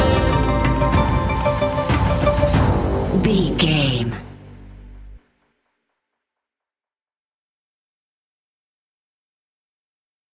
Heavy Synth & Percussion Stinger.
Aeolian/Minor
tension
ominous
dark
eerie
electronic music